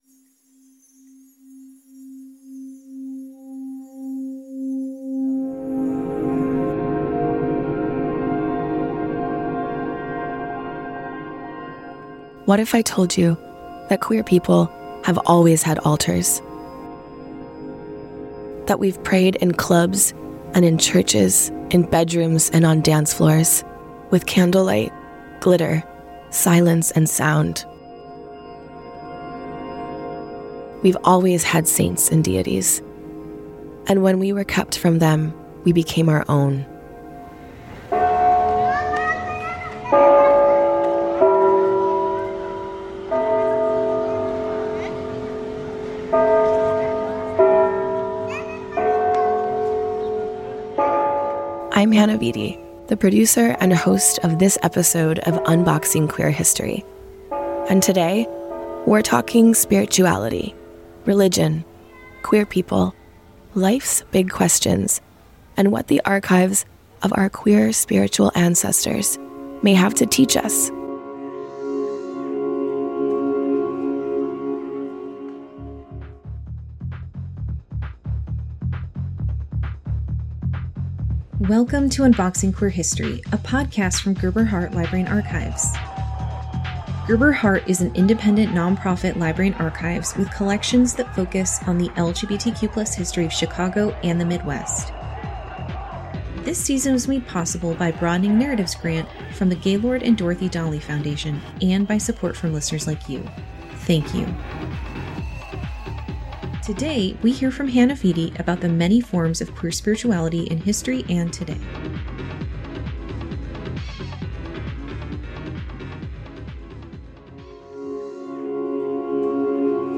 This episode explores the layered, often-hidden spiritual lives of queer and trans people with a special focus on voices from Chicago. Through oral histories and archival fragments, this episode chronicles the rituals we've remade, the faiths we've reclaimed, and the traces of spirit we continue to carry.